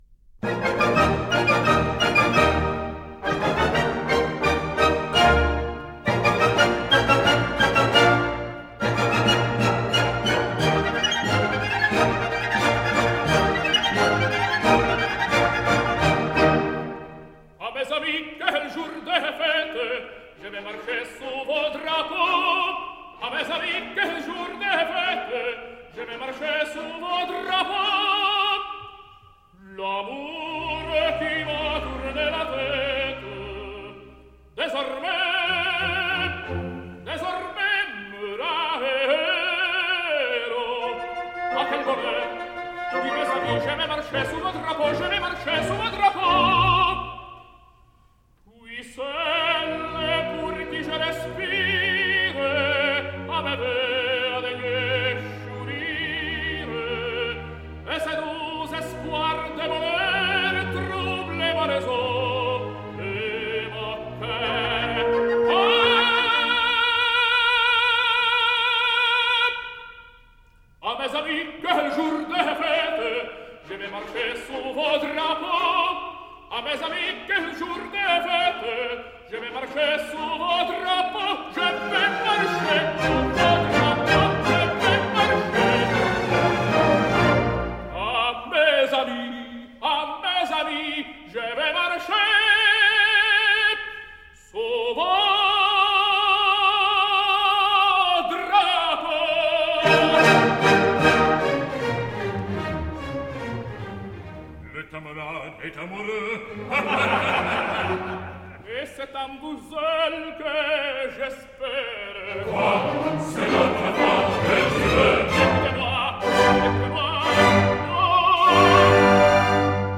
Italian tenor.
h! Mes Ami, quel jour de fete, which contained no less than nine high C’s within a single line.